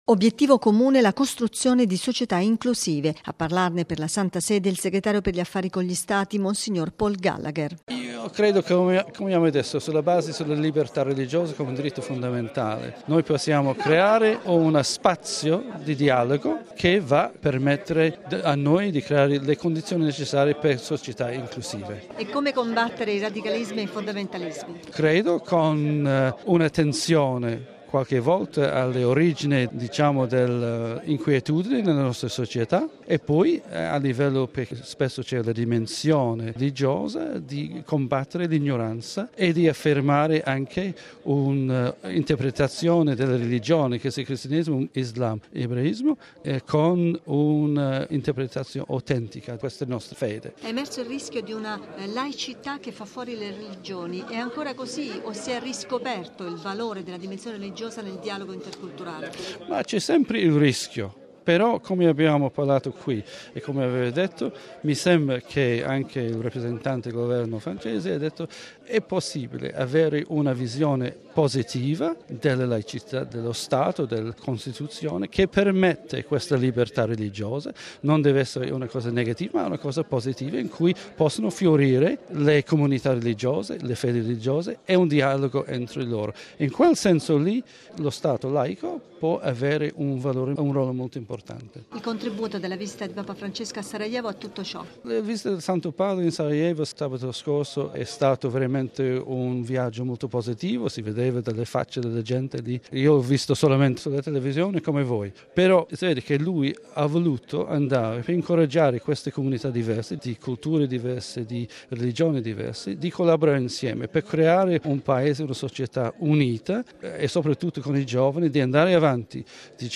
Da Strasburgo